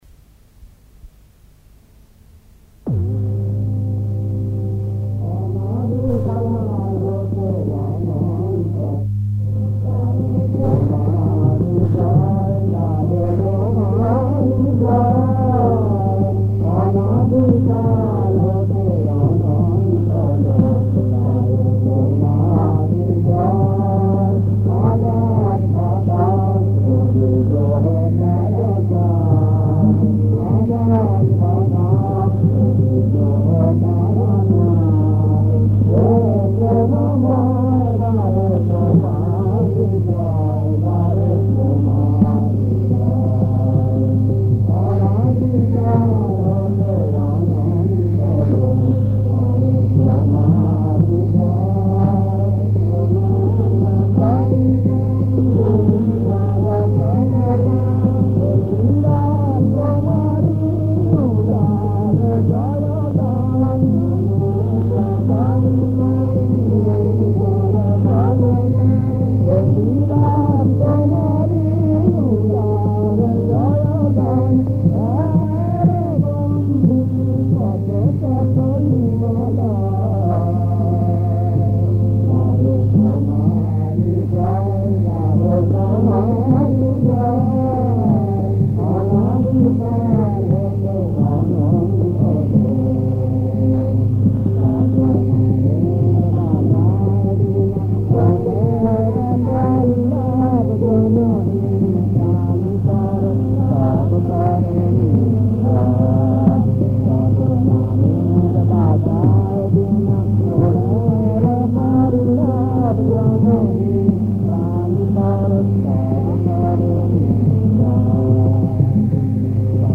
• বিষয়াঙ্গ: ধর্মসঙ্গীত, সাধারণ